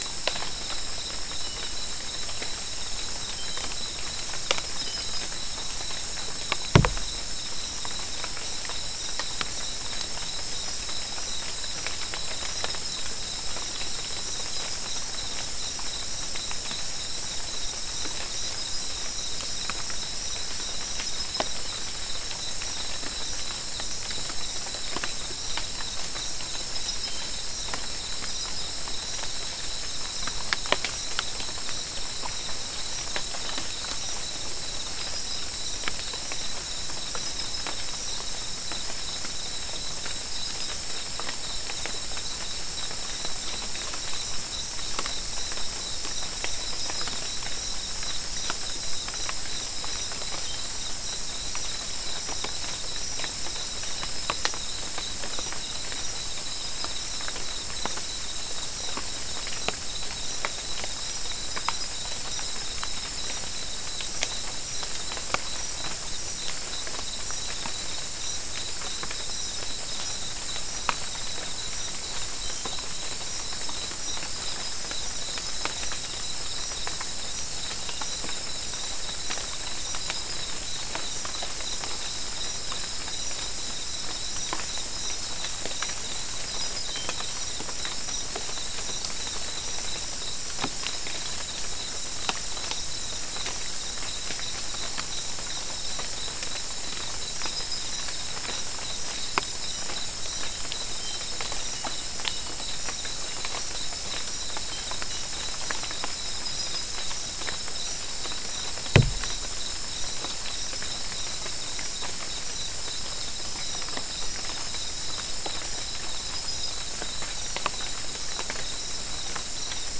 Non-specimen recording: Soundscape Recording Location: South America: Guyana: Kabocalli: 2
Recorder: SM3